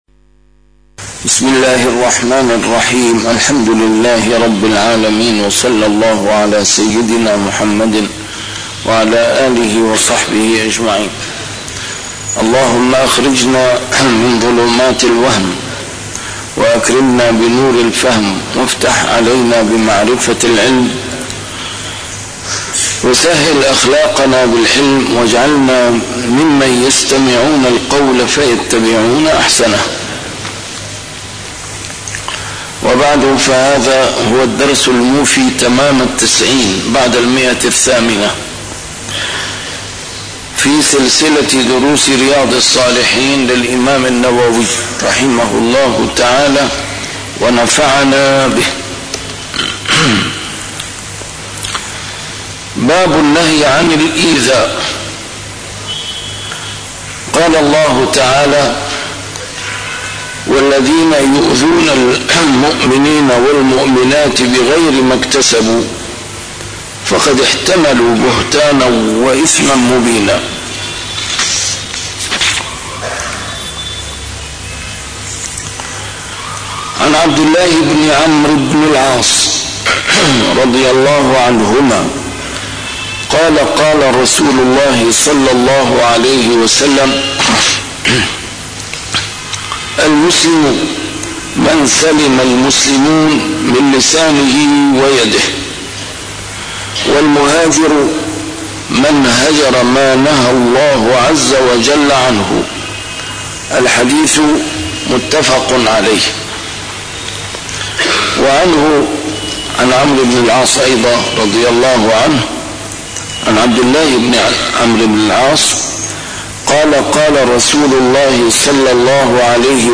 شرح كتاب رياض الصالحين - A MARTYR SCHOLAR: IMAM MUHAMMAD SAEED RAMADAN AL-BOUTI - الدروس العلمية - علوم الحديث الشريف - 890- شرح كتاب رياض الصالحين: النهي عن الإيذاء